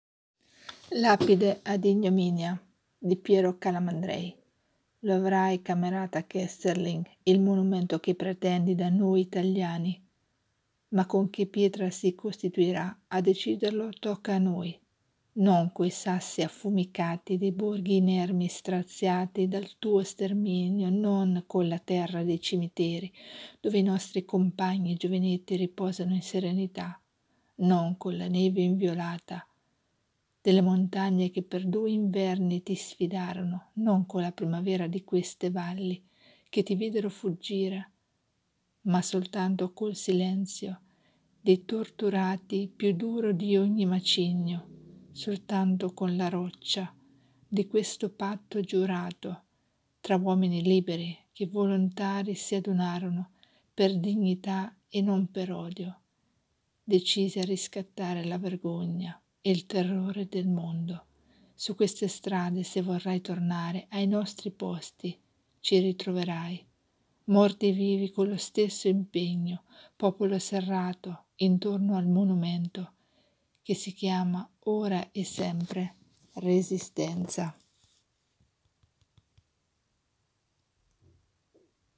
Lapide ad ignominia - lettura